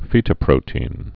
(fētə-prōtēn, -tē-ĭn)